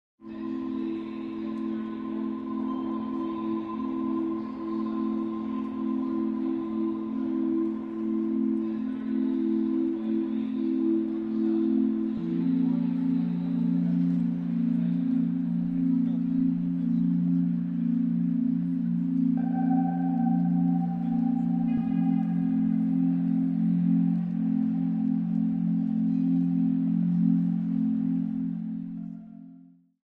The FocusScape I used was a “central railway station.”
First up, here is a 30 second clip of the “Central Terminal” FocusScape.